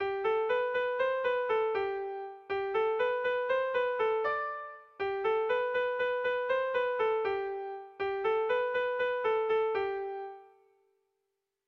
Sentimenduzkoa
Kopla doinua.
Kopla handia
8A / 8A / 10 / 8A (hg) | 8A / 8A / 18A (ip)
A1A2A3